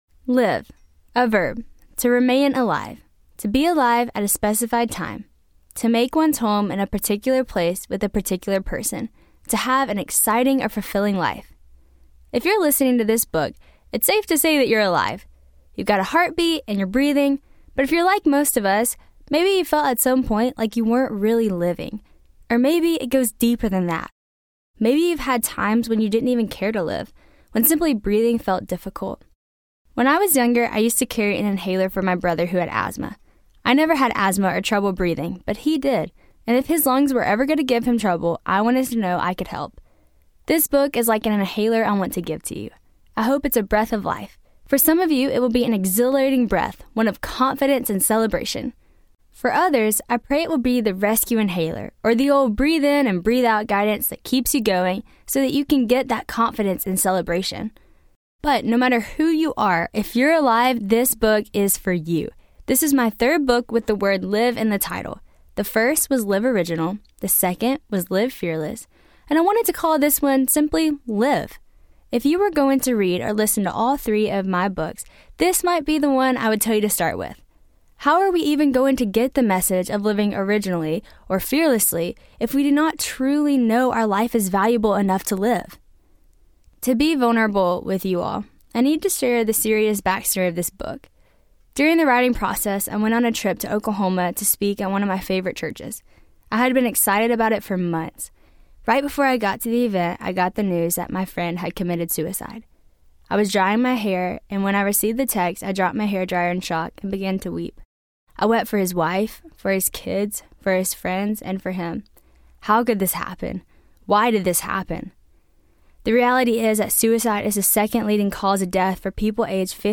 Live Audiobook
4.8 Hrs. – Unabridged